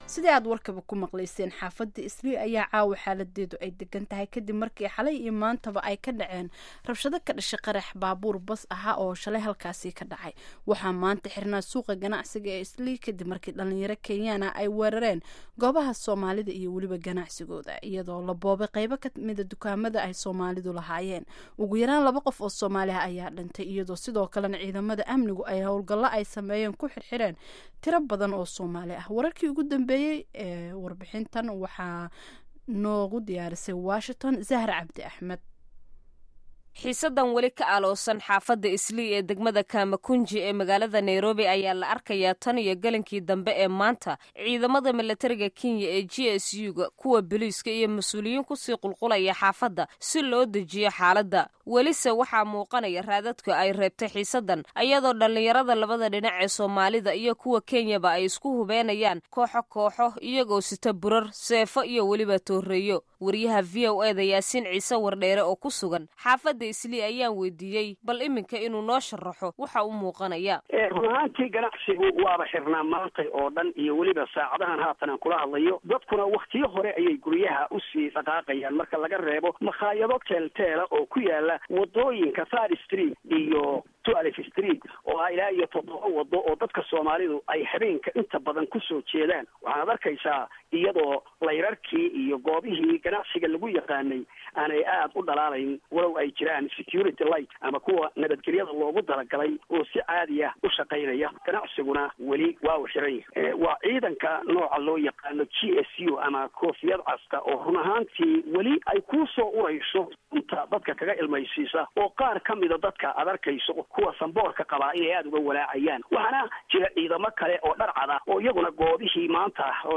Dhageyso warbixinta Islii iyo Wareysiga Faarax Macalin